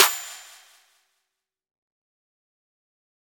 Snr Clp W Tri.wav